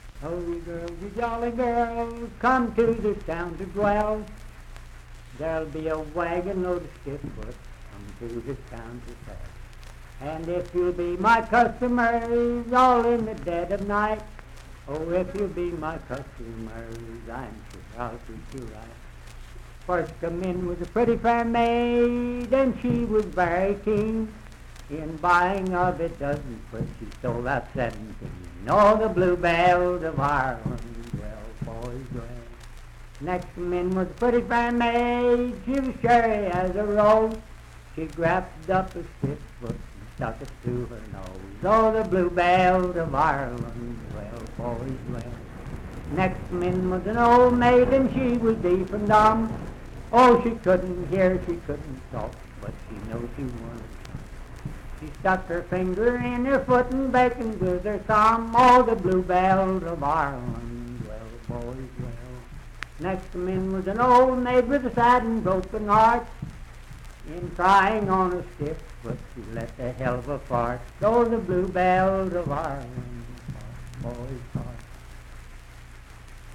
Unaccompanied vocal music
Bawdy Songs
Voice (sung)
Wood County (W. Va.), Parkersburg (W. Va.)